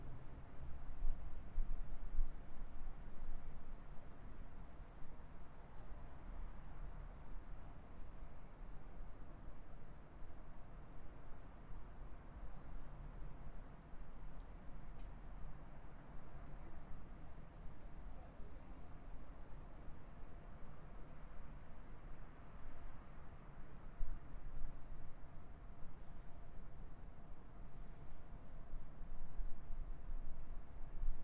中学外部环境（夜景）
描述：晚上音乐会结束后在一所中学外面的现场录音。人们说话，汽车拉出等等。用Zoom H4N记录。